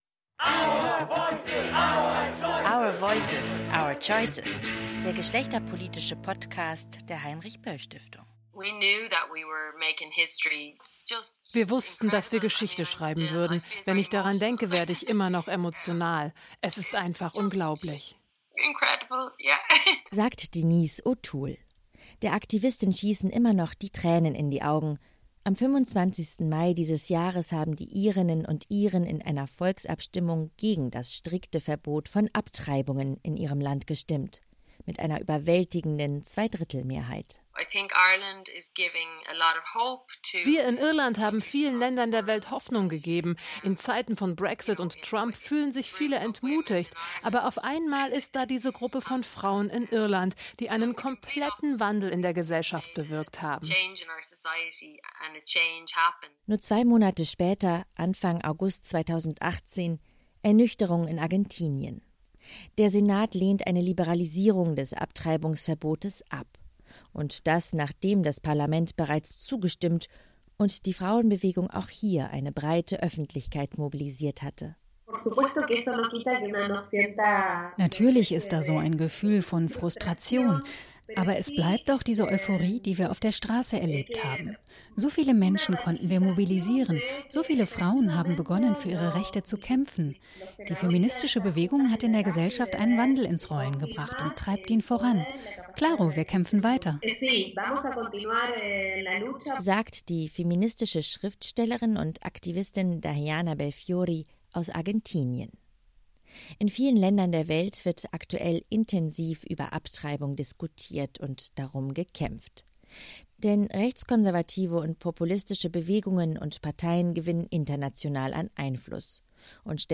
mit Aktivistinnen aus diesen drei Ländern gesprochen